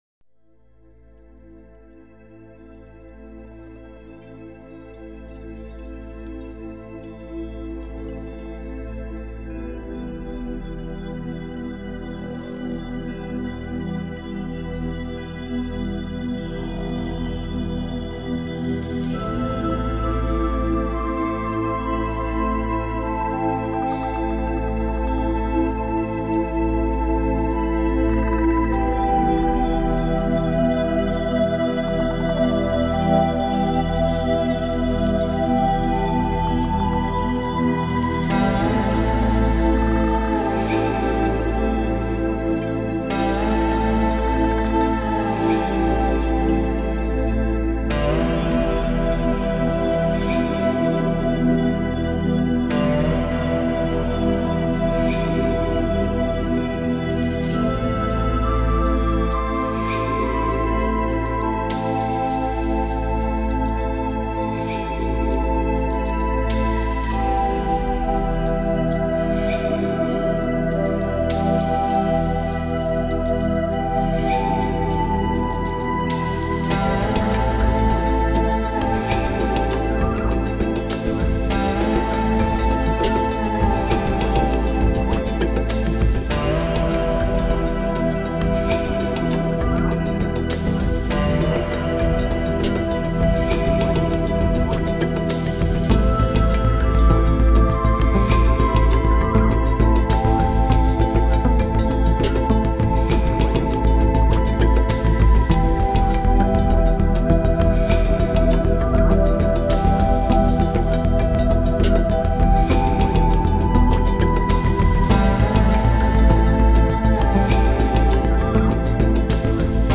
موسیقی آرام بخش موسیقی بی کلام
new age